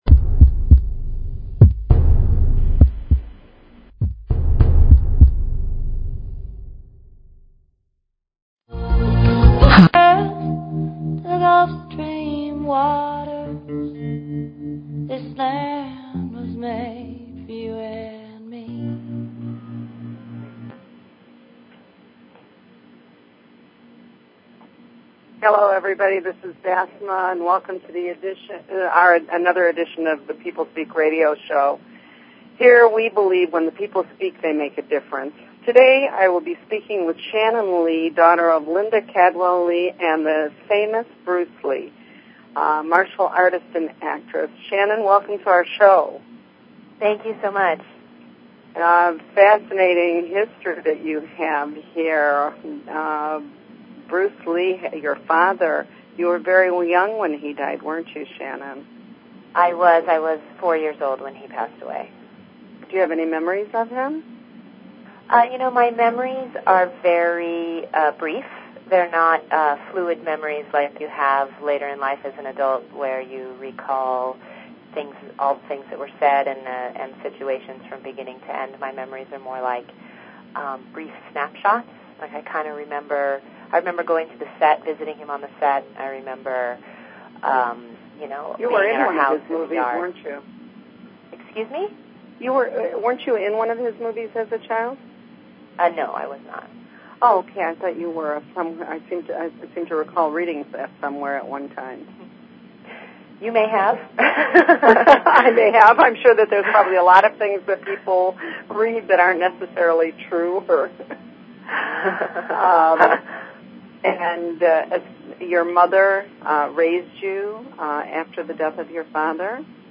Guest, Shannon Lee